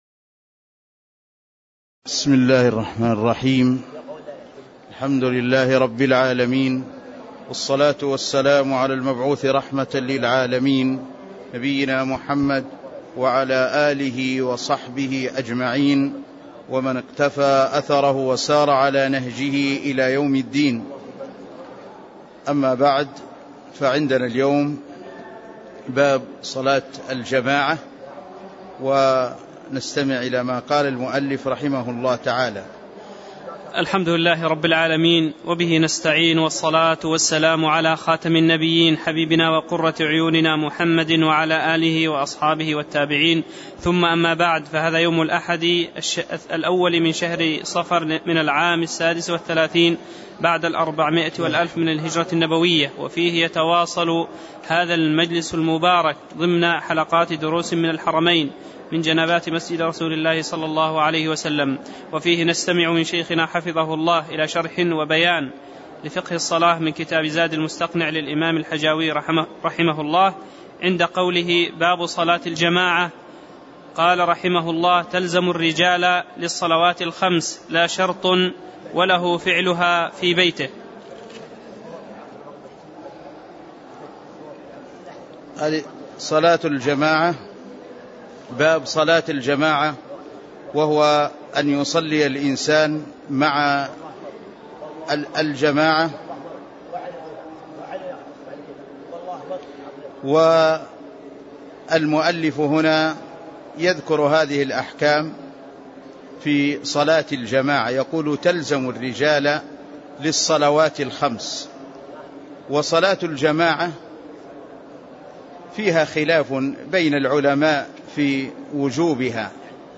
تاريخ النشر ١ صفر ١٤٣٦ هـ المكان: المسجد النبوي الشيخ